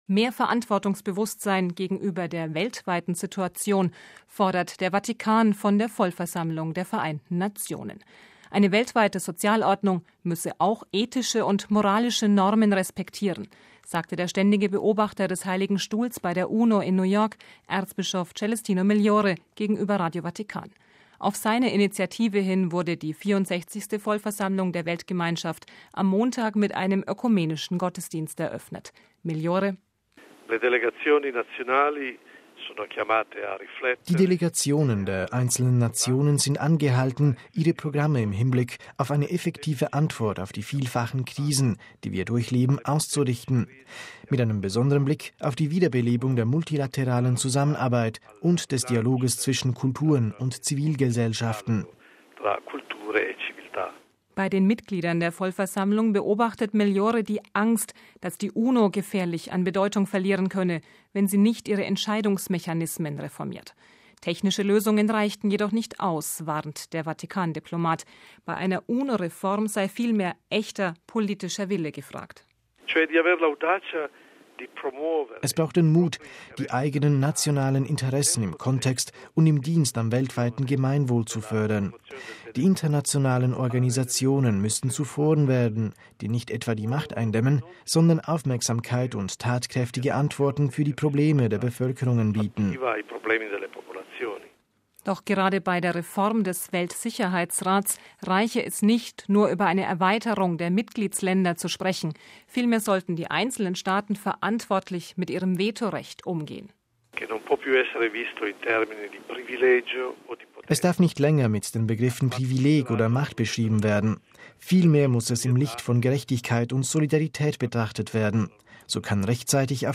MP3 Mehr Verantwortungsbewusstsein gegenüber der weltweiten Situation fordert der Vatikan von der Vollversammlung der Vereinten Nationen. Eine weltweite Sozialordnung müsse auch ethische und moralische Normen respektieren, sagte der Ständige Beobachter des Heiligen Stuhls bei der UNO in New York, Erzbischof Celestino Migliore, gegenüber Radio Vatikan.